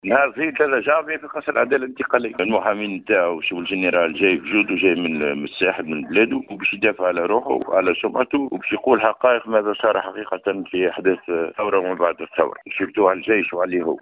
في تصريح خص به ام اف ام